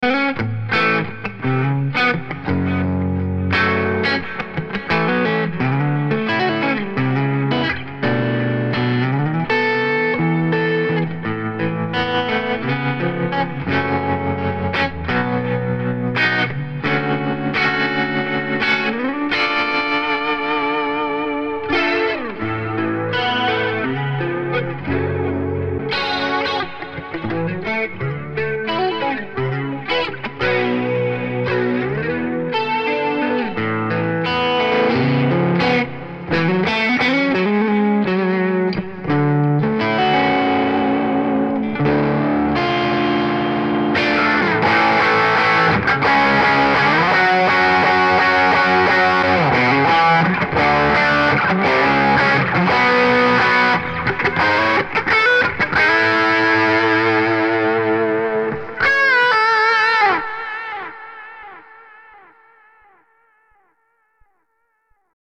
This Amp Clone rig pack is made from an Tone King Imperial MKII preamp.
RAW AUDIO CLIPS ONLY, NO POST-PROCESSING EFFECTS